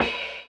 LO FI 9 OH.wav